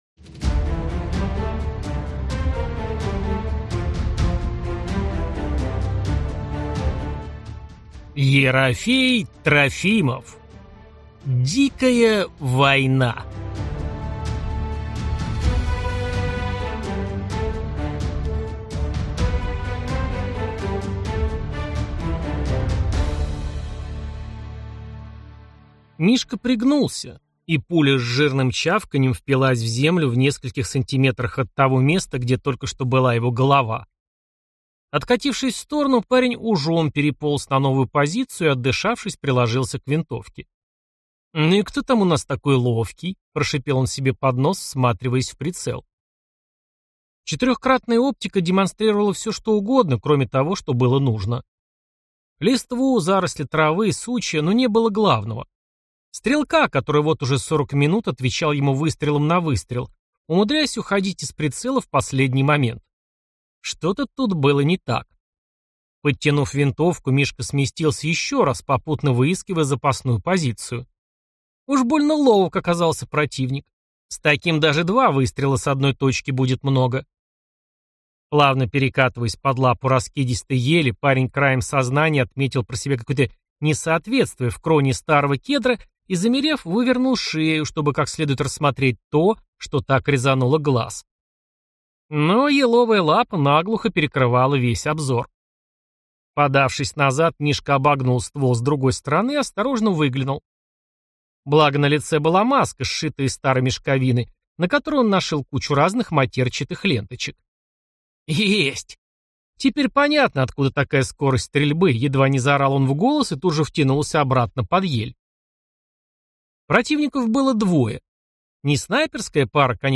Аудиокнига Дикая война | Библиотека аудиокниг